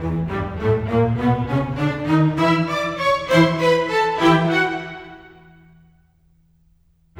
Rock-Pop 20 Strings 04.wav